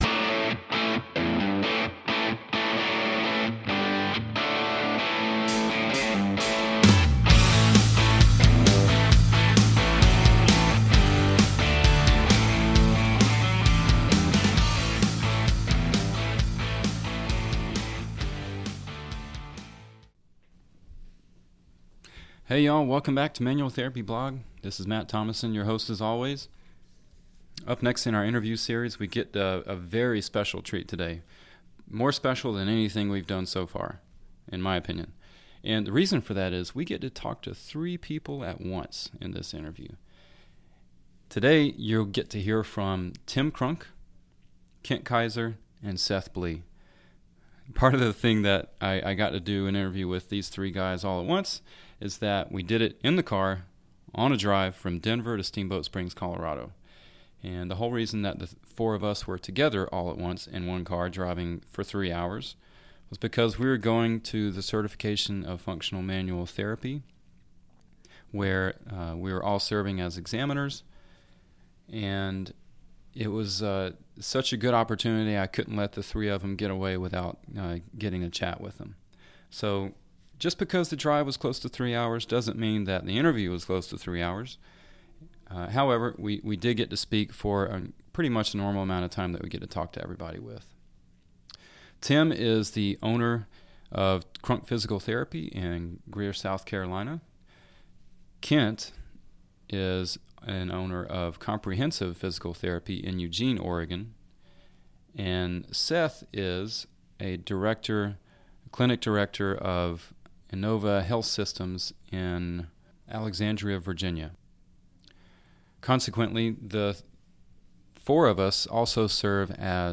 This conversation is with not one... but three people all at once.